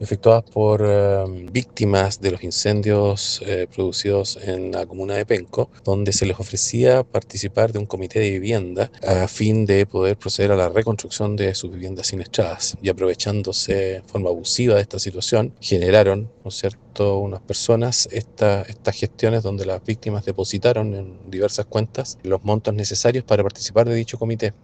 El fiscal jefe de la Fiscalía de Flagrancia de la Región del Biobío, Gonzalo Guerrero, se refirió a la denuncia de las víctimas y al modo de operar de los autores.